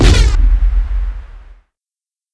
explo17b_shake.wav